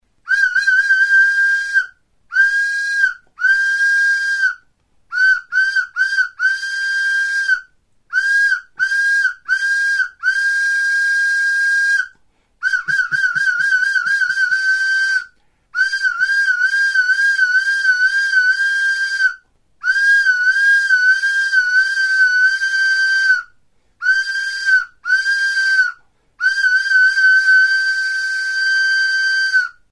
TXULUBITA (Pistoi flauta) | Soinuenea Herri Musikaren Txokoa
(Grabatzerakoan txulubita honen pistoi sistemak ez zuen funtzionatzen)
Enregistré avec cet instrument de musique.